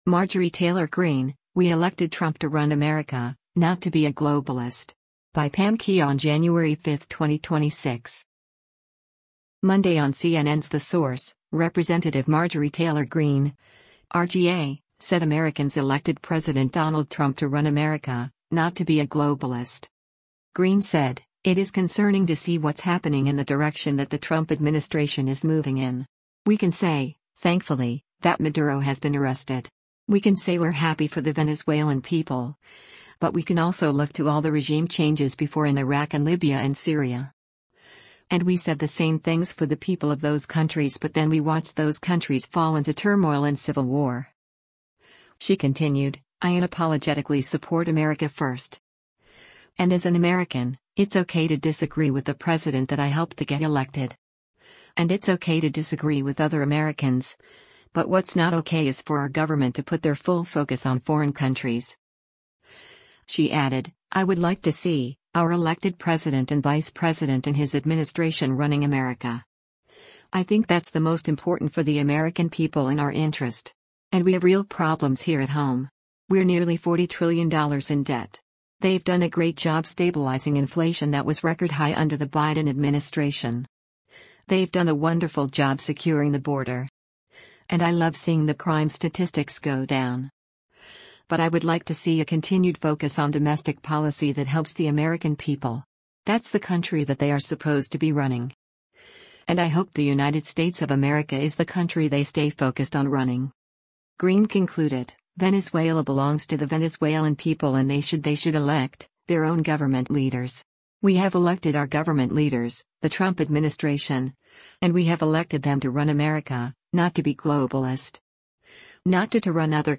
Monday on CNN’s “The Source,” Rep. Marjorie Taylor Greene (R-GA) said Americans elected President Donald Trump to “run America,” not to be a “globalist.”